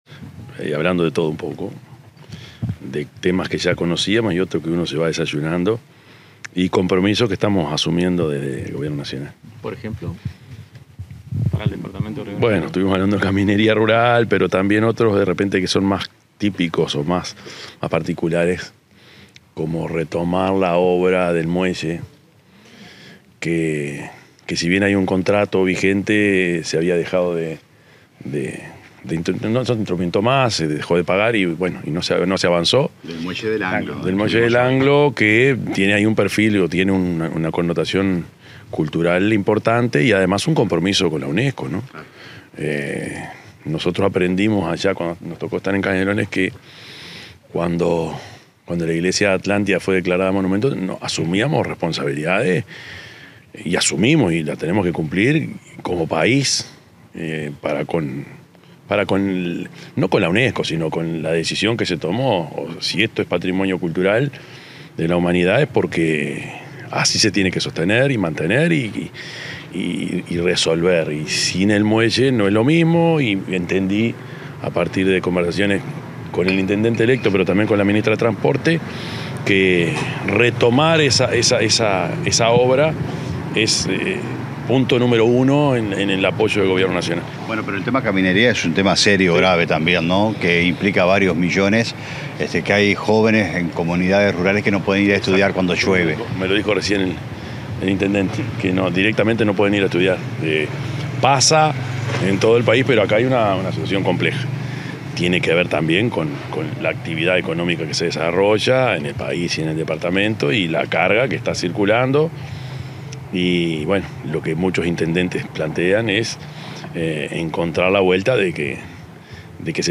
Declaraciones del presidente de la República, Yamandú Orsi
El presidente de la República, profesor Yamandú Orsi, dialogó con la prensa rras mantener una reunión con el intendente electo de Río Negro, Guillermo
Orsi_declaraciones.mp3